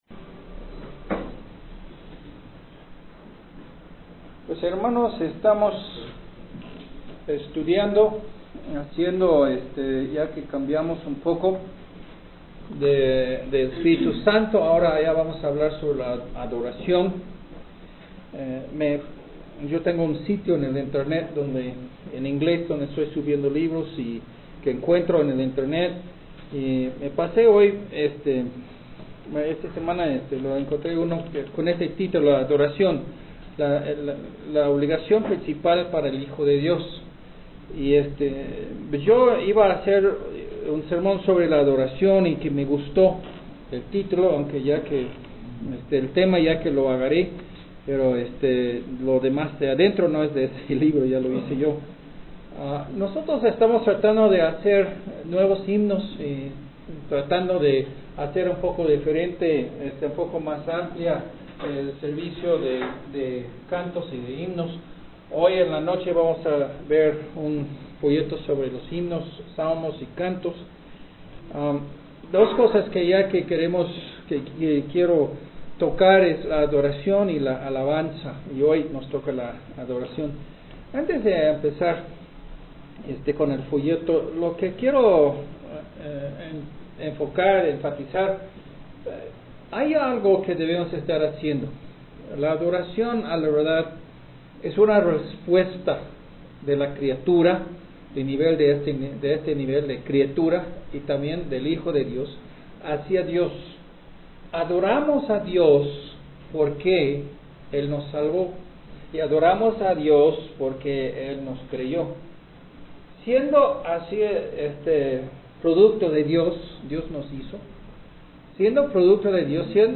ig12 Adoración Sermón en Audio